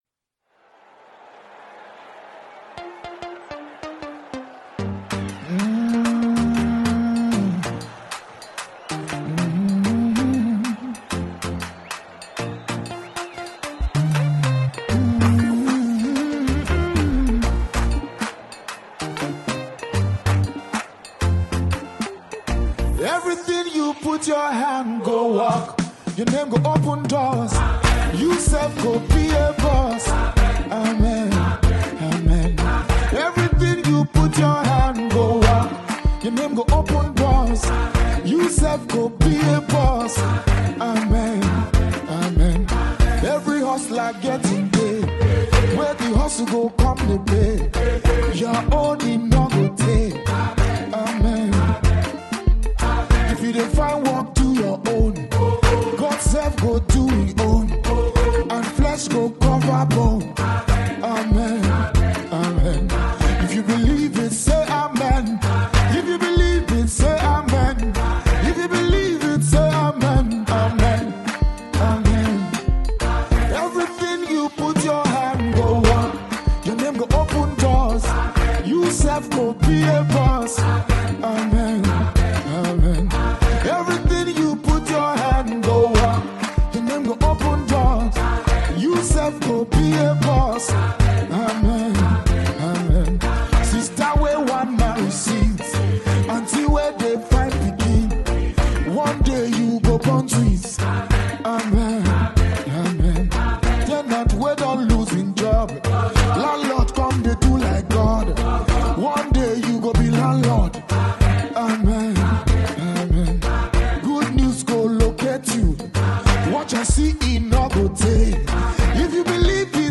Contemporary gospel
soul lifting